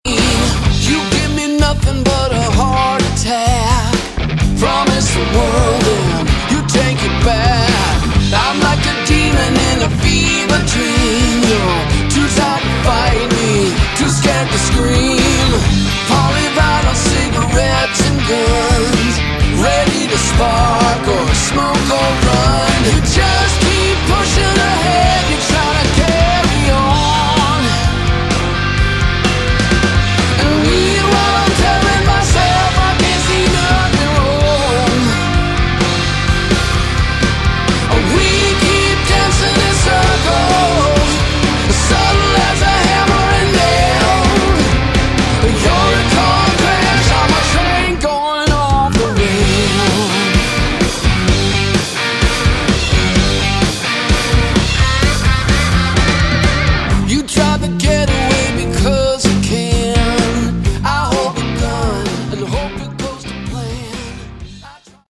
Category: Hard Rock
vocals, guitar
drums